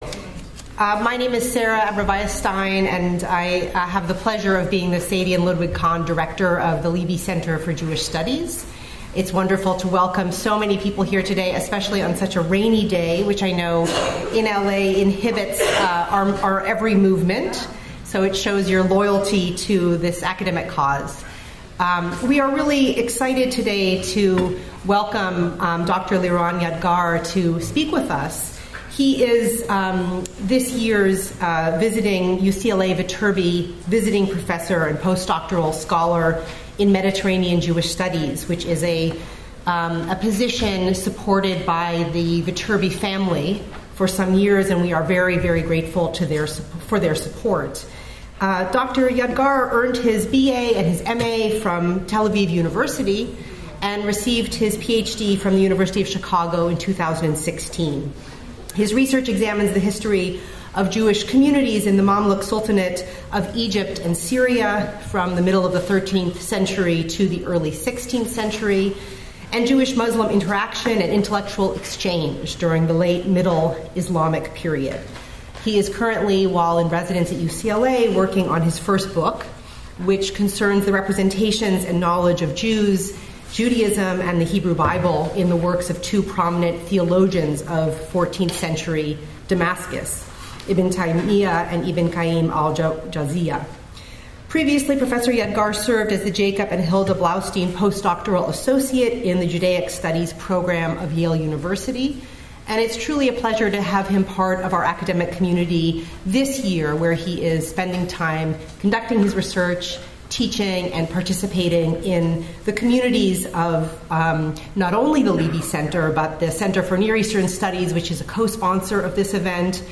This lecture will follow the legend of Khaybar from the early Islamic period to the twentieth century.